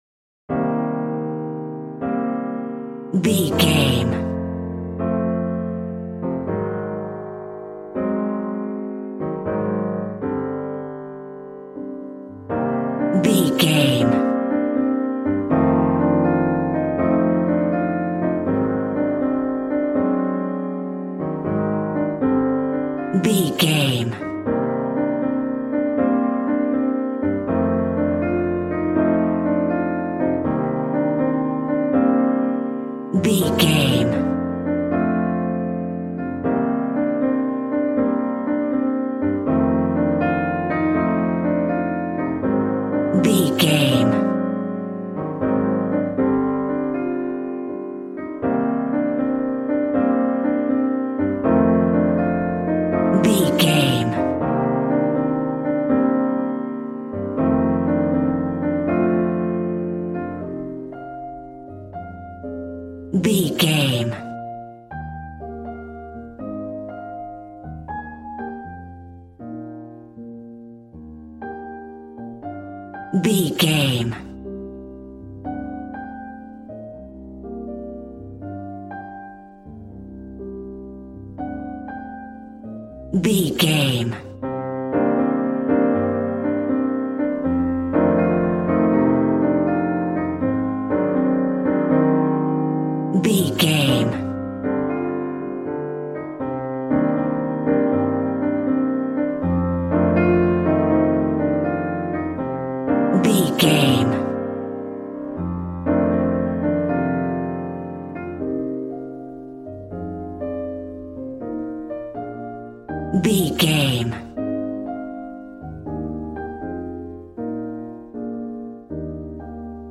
Ionian/Major
sexy
smooth
cool
piano
drums